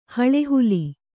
♪ haḷe huli